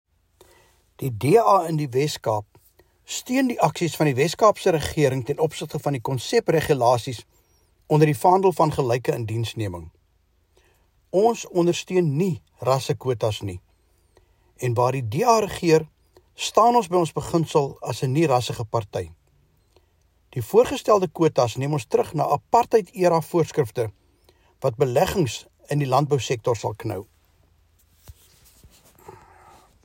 English soundbites from MPP Andricus van der Westhuizen attached.